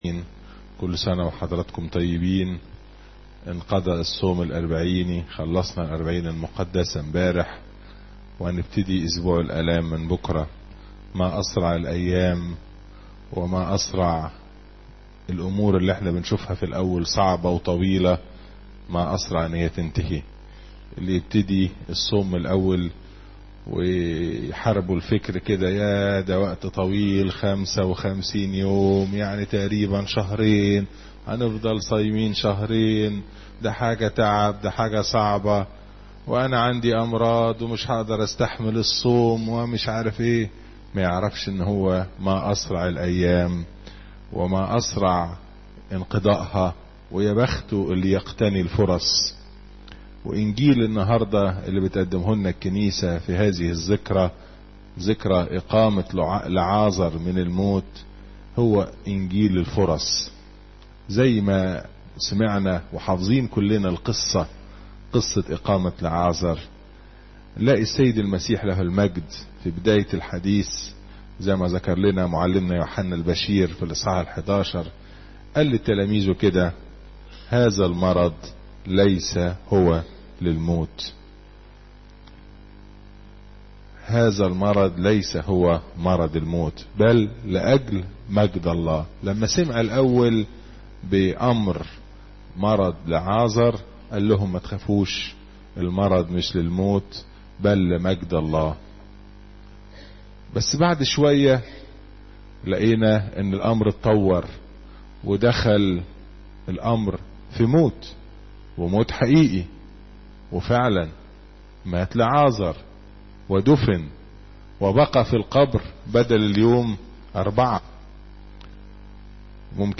عظات اسبوع الآلام